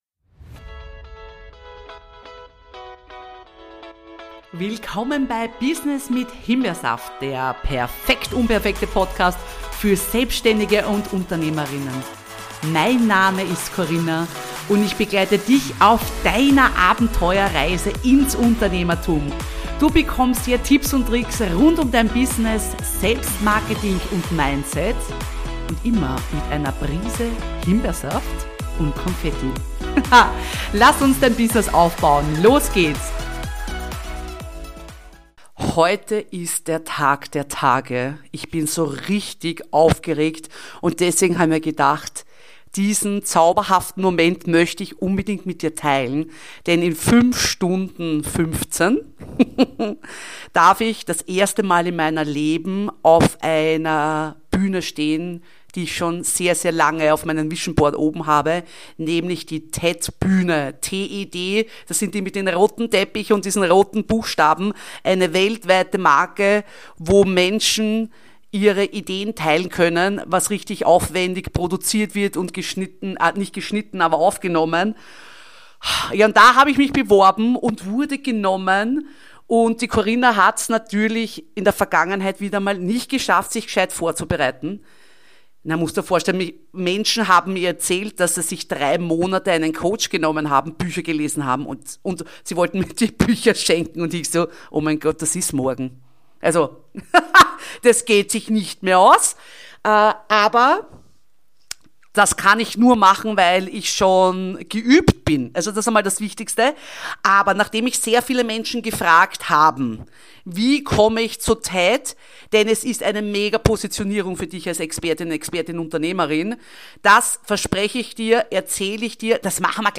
133 - TED-Talk Premiere: Killt KI unsere Persönlichkeit?